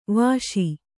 ♪ vāśi